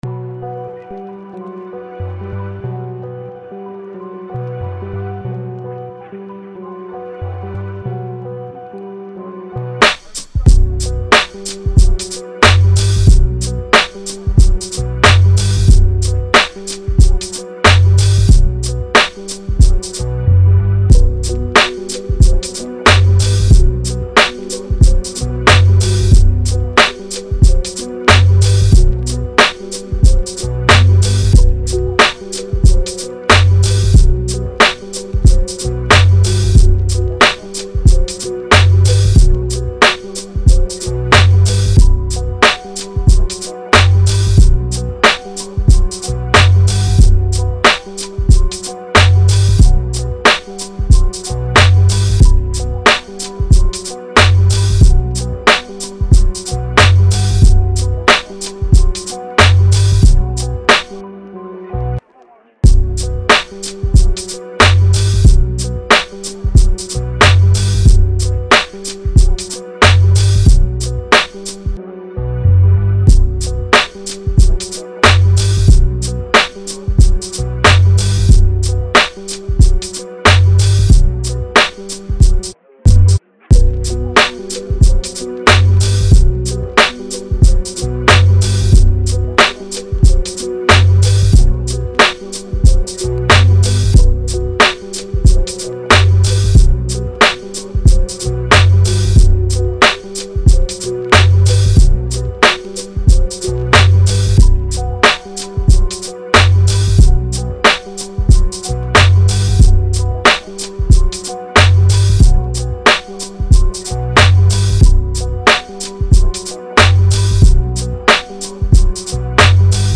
90s, Hip Hop
B minor